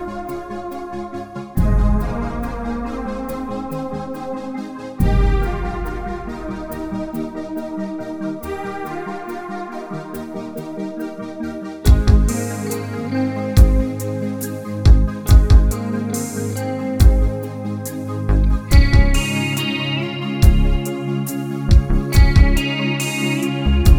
Backing Vocals Pop (1990s) 4:26 Buy £1.50